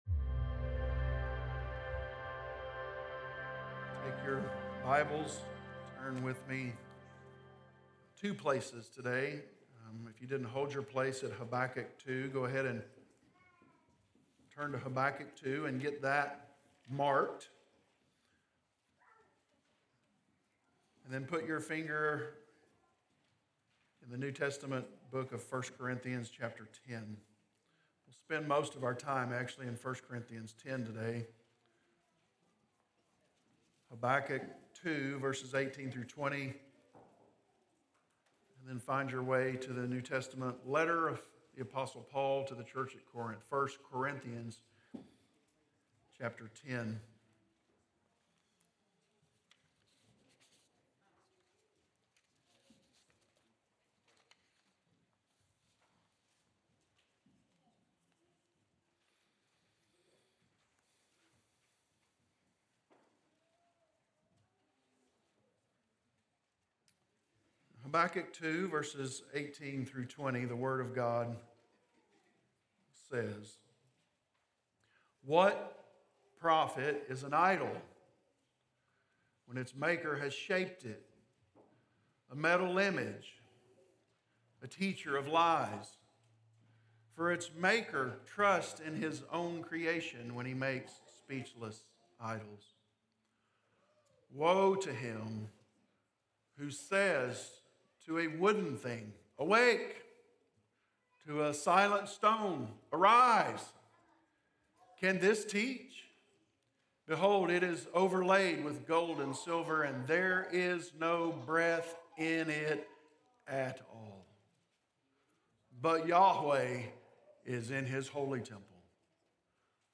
Explore other Sermon Series
Sermons recorded during the Sunday morning service at Corydon Baptist Church in Corydon, Indiana